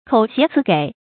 口谐辞给 kǒu xié cí gěi 成语解释 谓言辞诙谐敏捷。